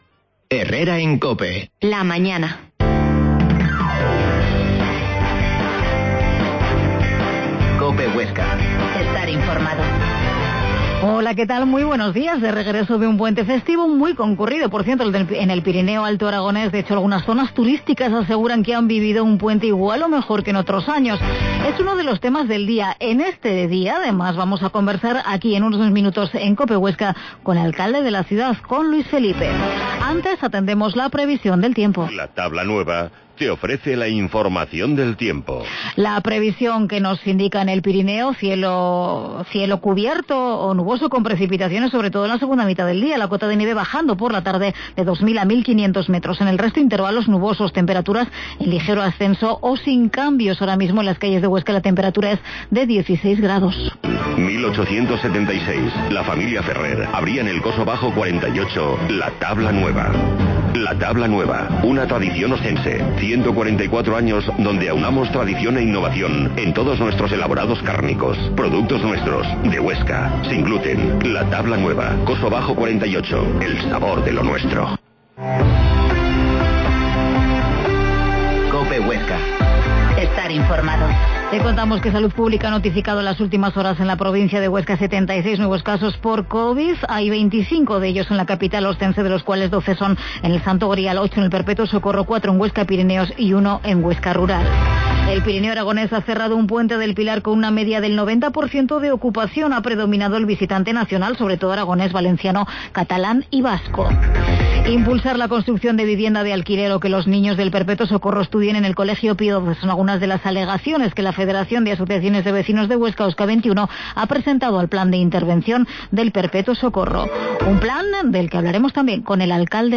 Herrera en COPE Huesca 12.50h Entrevista al alcalde de Huesca,Luis Felipe